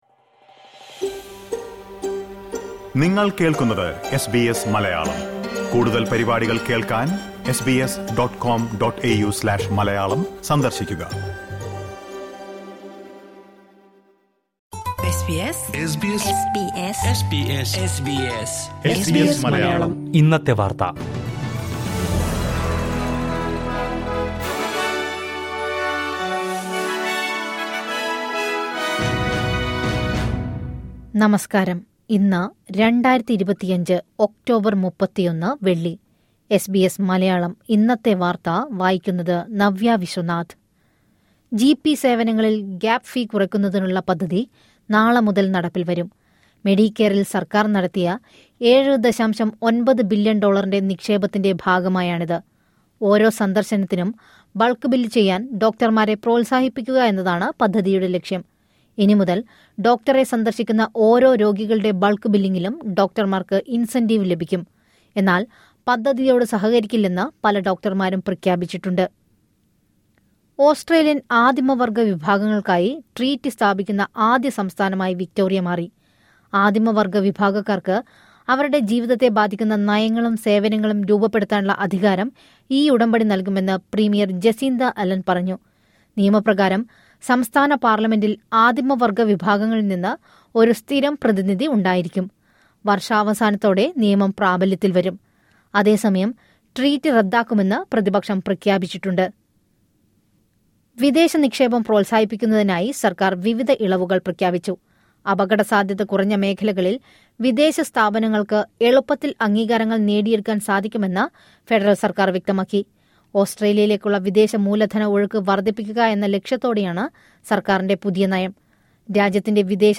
2025 ഒക്ടോബർ 31ലെ ഓസ്ട്രേലിയയിലെ ഏറ്റവും പ്രധാന വാർത്തകൾ കേൾക്കാം...